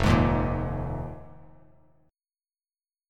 Fm11 chord